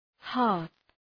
Προφορά
{hɑ:rɵ}